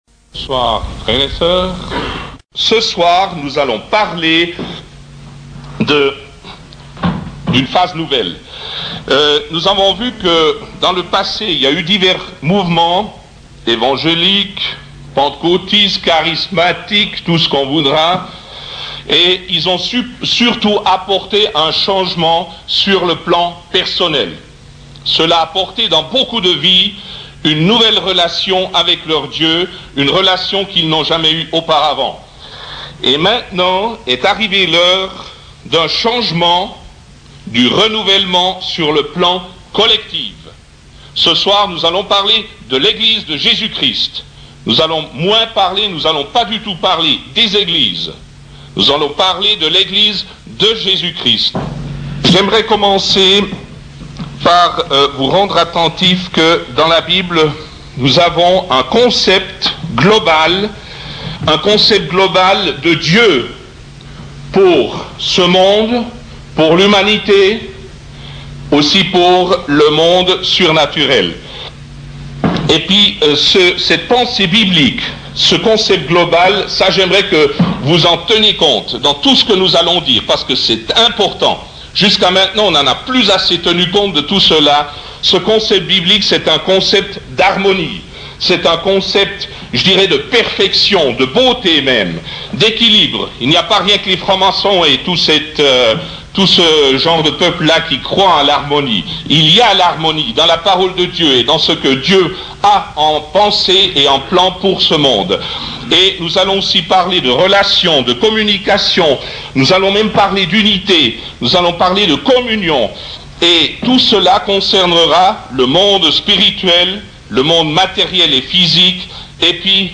Premier enseignement fondateur de l'Œuvre APV sur la vision de l'Eglise, apporté dans le cadre d'une convention chrétienne à Avenches, le 13.02.1987. Merci de prendre en compte le correctif sur deux points du début de message : le récit de la création et le diable.